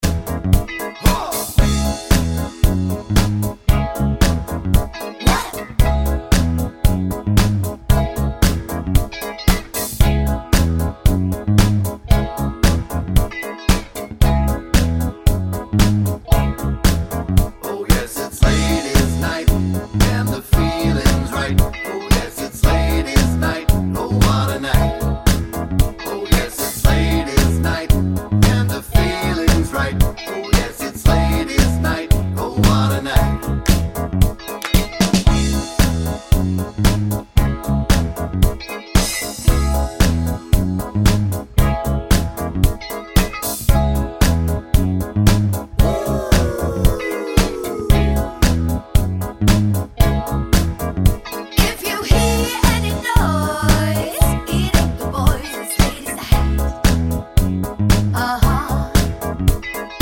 no Backing Vocals Disco 3:15 Buy £1.50